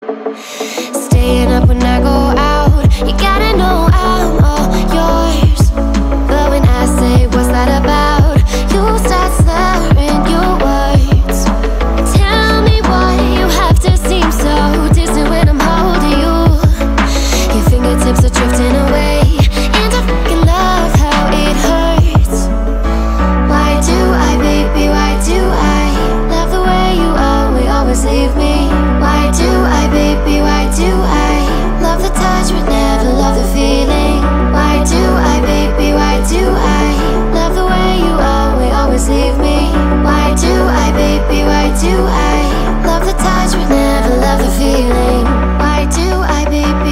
Categoría Electrónica